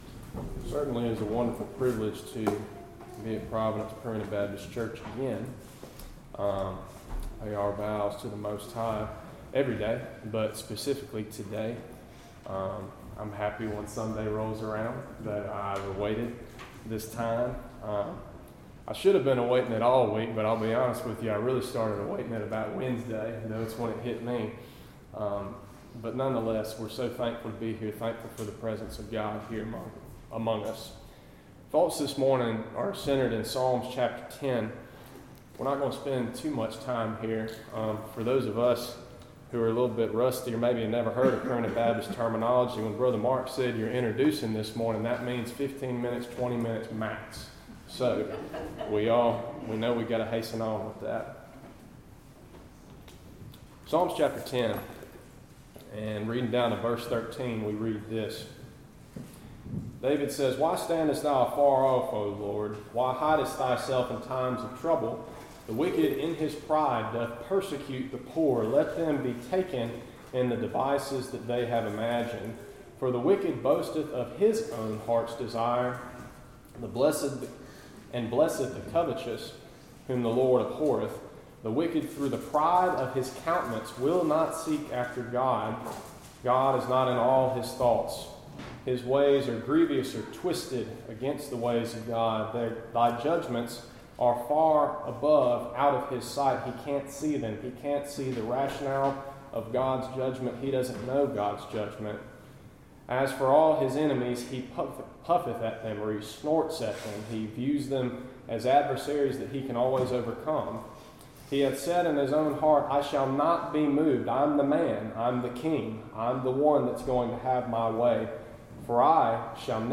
Gifts in the Church Topic: Sermons